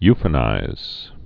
(yfə-nīz)